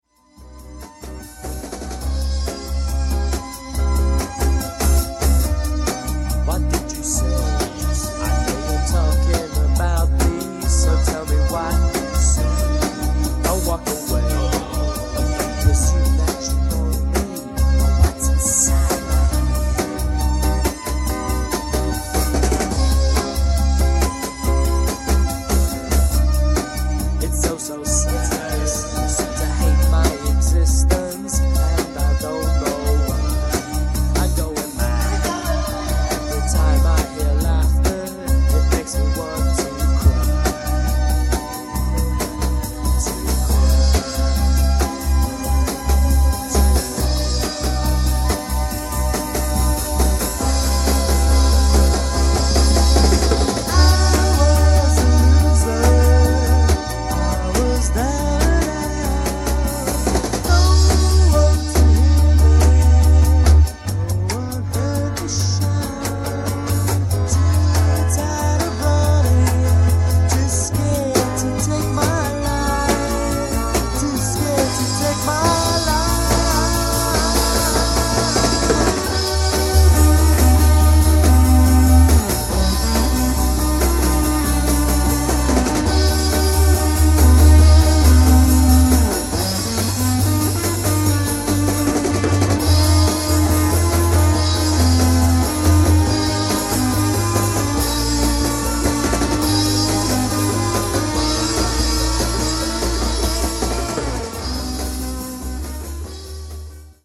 Guitar, vocals
Keyboards, vocals
Drums, vocals